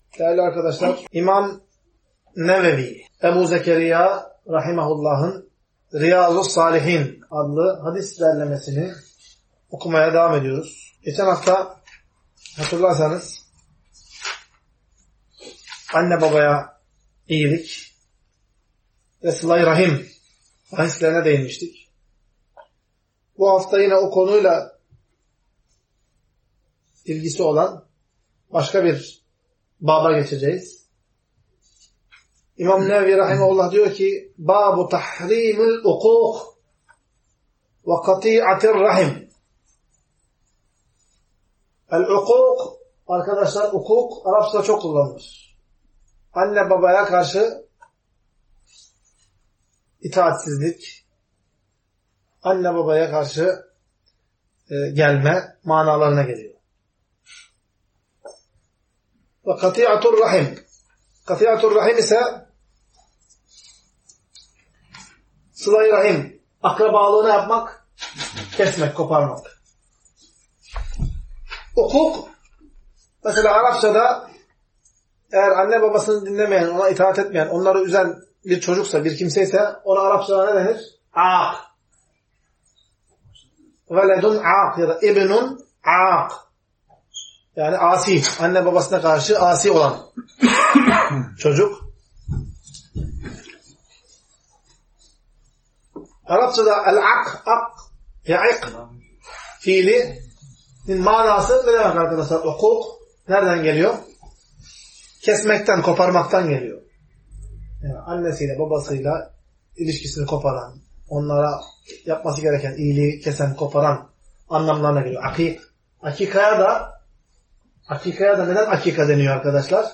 Ders - 41.